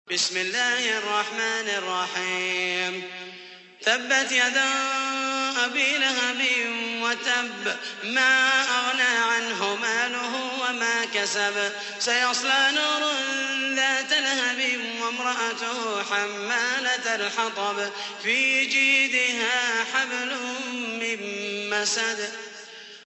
تحميل : 111. سورة المسد / القارئ محمد المحيسني / القرآن الكريم / موقع يا حسين